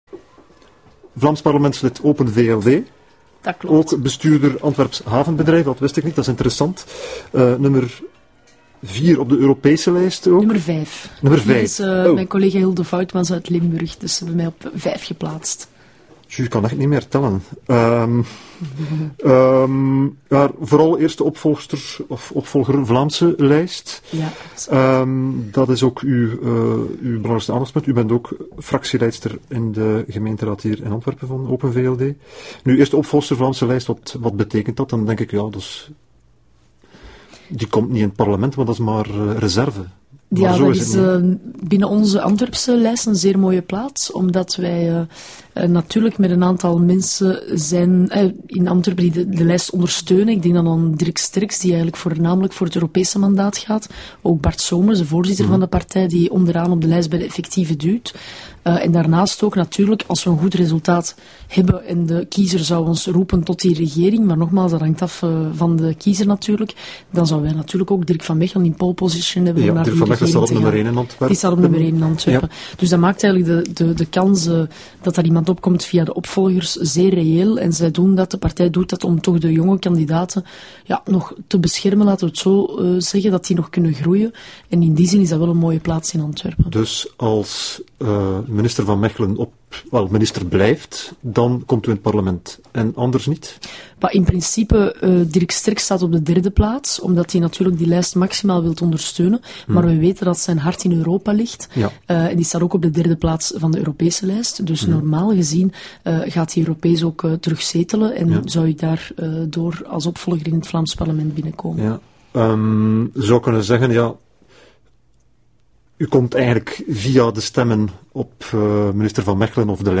We hebben het eerst over het Vlaams mobiliteitsbeleid en daarna meer specifiek over het Oosterweeldossier. Een stevig debat over een belangrijk stuk Vlaams beleid, waarbij onze gaste niet op haar kop laat zitten.